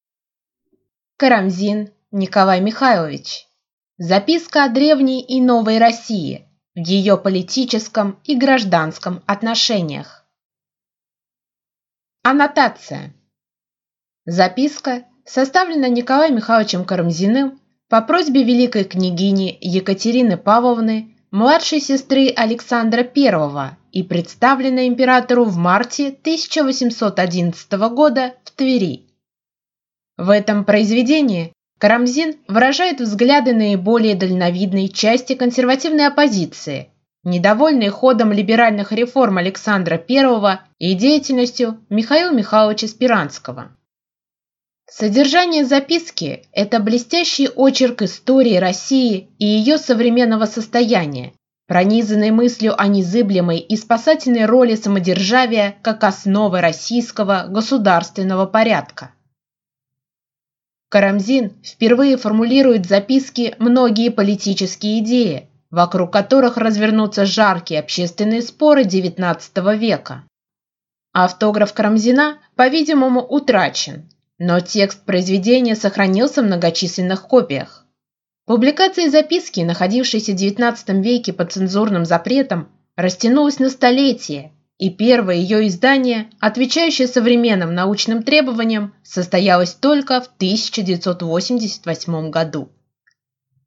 Аудиокнига Записка о древней и новой России в ее политическом и гражданском отношениях | Библиотека аудиокниг